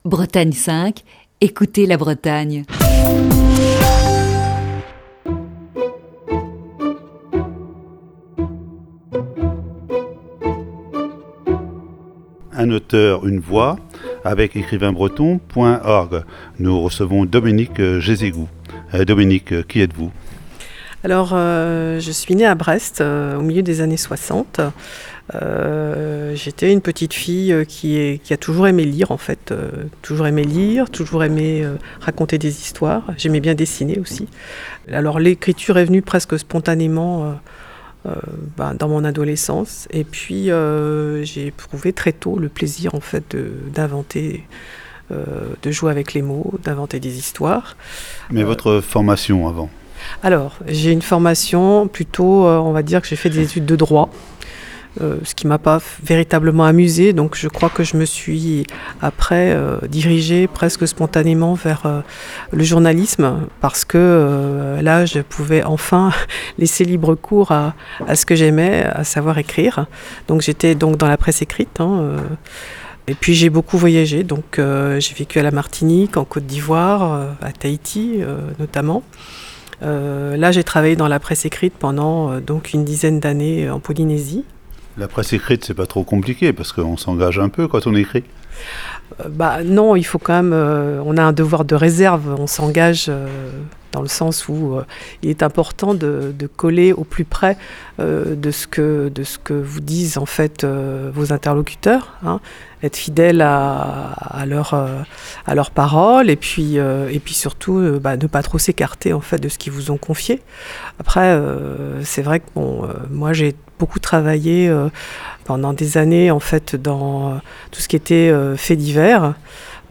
Ce lundi, voici la première partie de cet entretien diffusé le 9 décembre 2019.